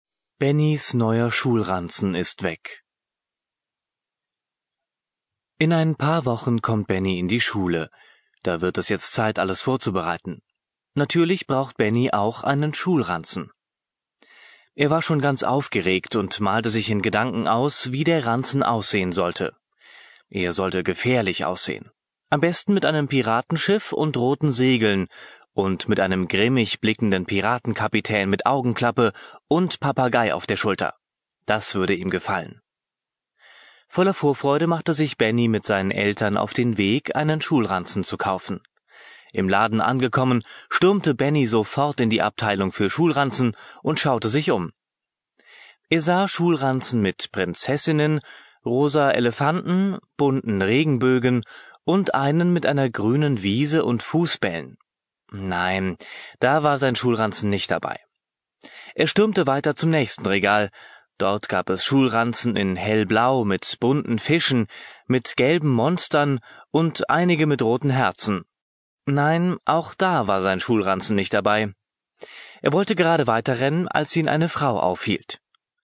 Alle Geschichten sind von deutschen Muttersprachlern gesprochen, um den Kindern ein Gefühl für die Sprachmelodie und Aussprache zu vermitteln.
Die Audio-CD Deutsche Kindergeschichten, Benni kommt in die Schule enthält 10 deutsche Kindergeschichten und richtet sich an alle Eltern, die ihre Kinder frühzeitig mit gutem und richtigem Deutsch vertraut machen möchten und die ihren Kindern die Möglichkeit geben möchten ihre sprachlichen Fähigkeiten so früh wie möglich zu entwickeln. Alle Geschichten sind von ausgebildeten Sprechern gesprochen, um den Kindern ein Gefühl für die Sprachmelodie und Aussprache des Hochdeutschen zu vermitteln.